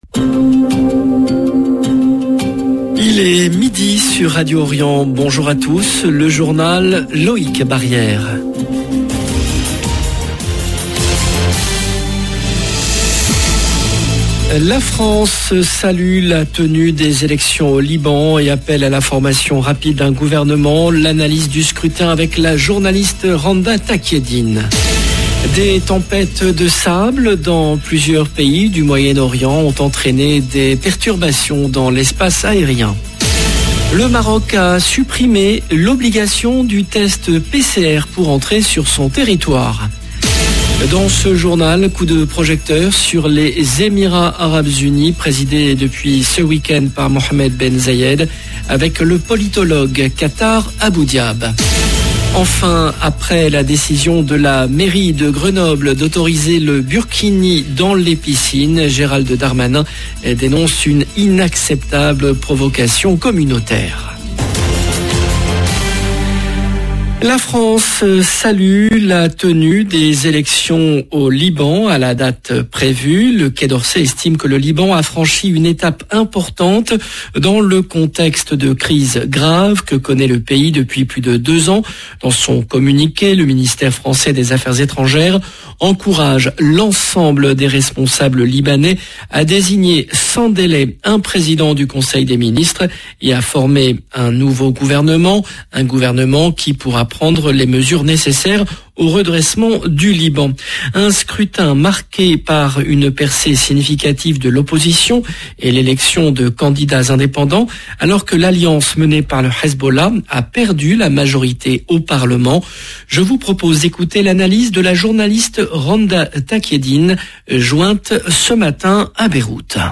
LE JOURNAL DE LA MI-JOURNEE EN LANGUE FRANCAISE DU 18/05/22